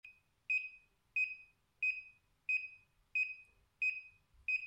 • Der Vario-Ton variiert in Frequenz und Tonhöhe je nach vertikaler Geschwindigkeit